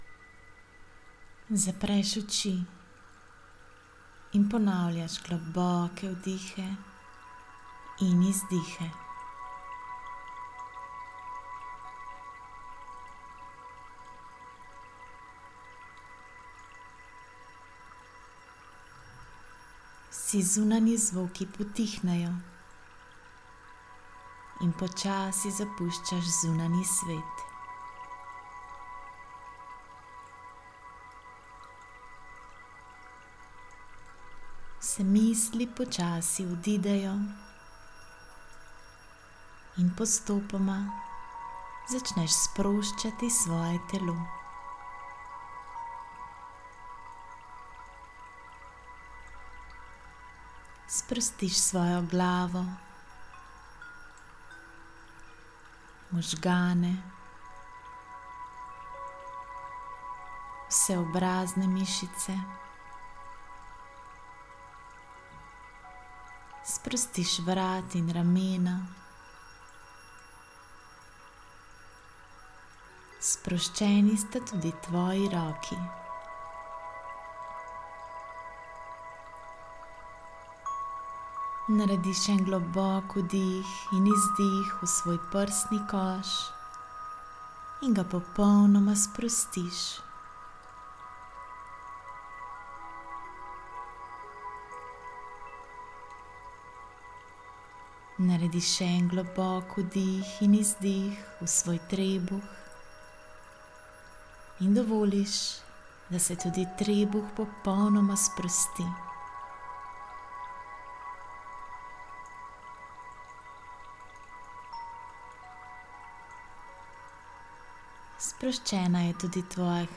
V včerajšnjem e-mailu, sem vam poslala brezplačno meditacijo , ki sem jo posnela za namen sproščanja strahu, umiritve in obnovitve energije.